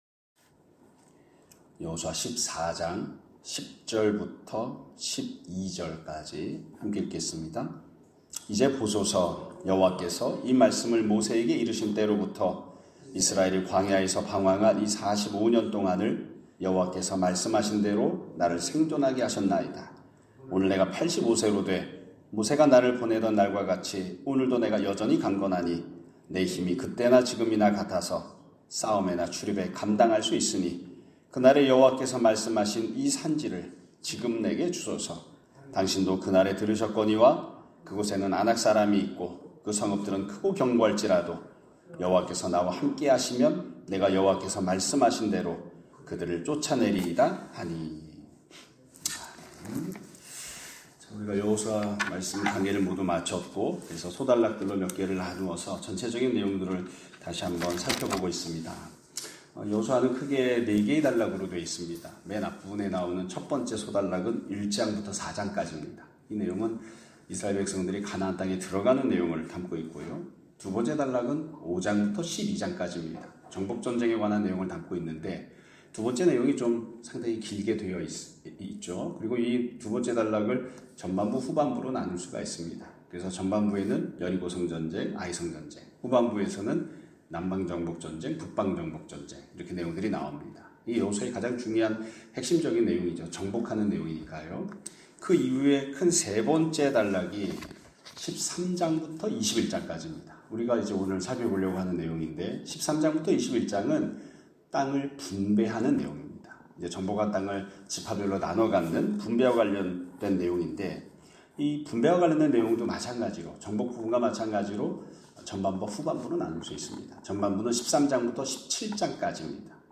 2025년 3월 13일(목요일) <아침예배> 설교입니다.